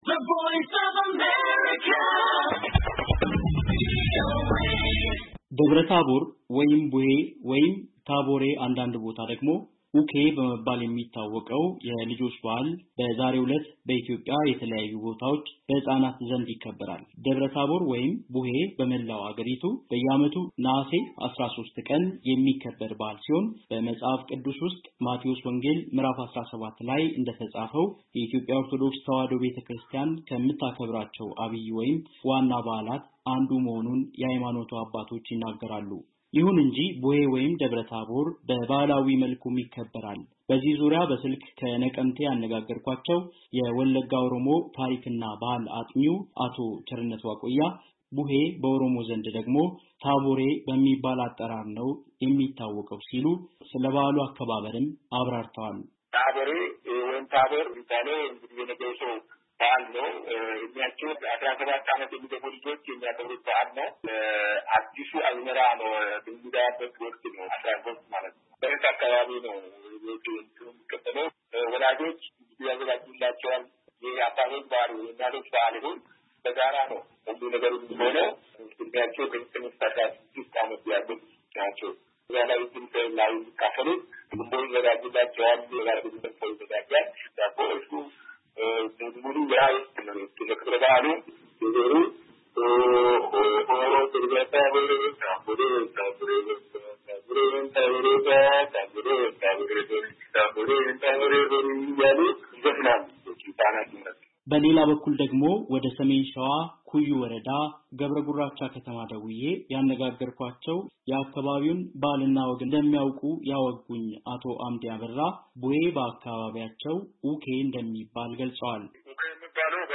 ደብረ ታቦር ወይም ቡሄ ወይም ታቦሬ አንዳንድ ቦታ ደግሞ ኡኬ ይባላል። ህፃናት የሚያከብሩት የልጆች በዓል ነው። በኣሉ በባህላዊ አከባበሩ ምን እንደሚመስል ከአምቦ ነቀምቴና ኩዩ የታሪክና ባህል ምሁር እንዲሁም ስለበኣሉ የሚያውቁ ሰዎች ይናገራሉ።